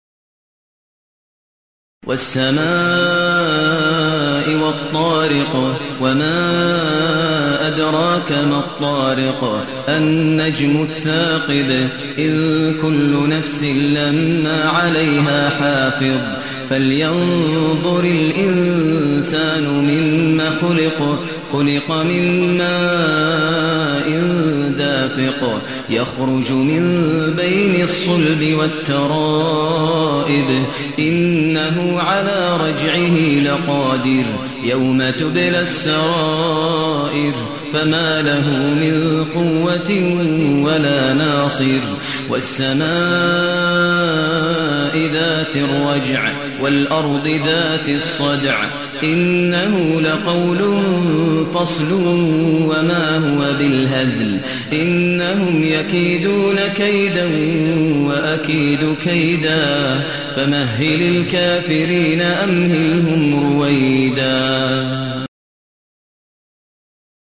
المكان: المسجد الحرام الشيخ: فضيلة الشيخ ماهر المعيقلي فضيلة الشيخ ماهر المعيقلي الطارق